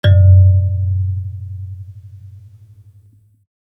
kalimba_bass-F#1-pp.wav